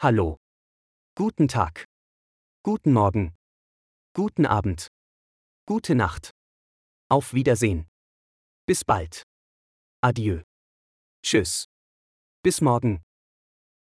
سنضع لكم لفظ كل كلمة مكتوبة بالعربي وايضاً صوتياً لأن كلمات المانية مكتوبة بالعربي تساعد المبتدئين في تعلم اللغة الألمانية بشكل أفضل وأسرع.